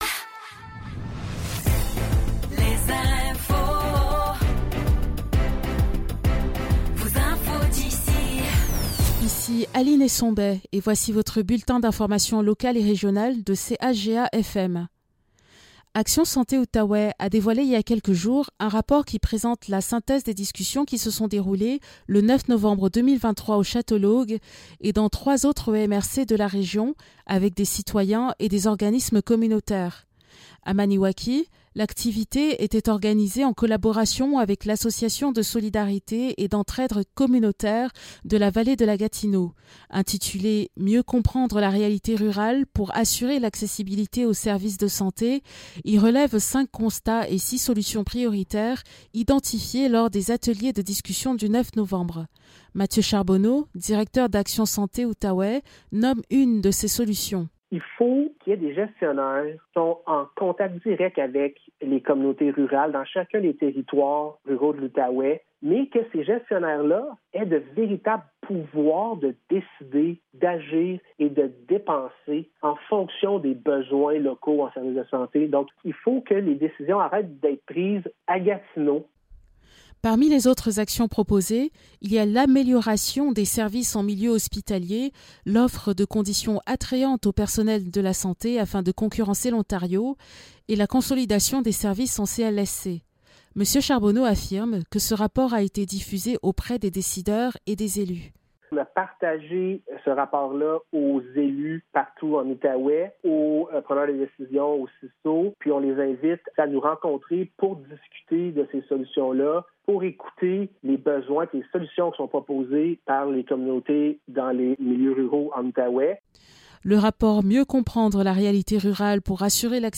Nouvelles locales - 23 février 2024 - 12 h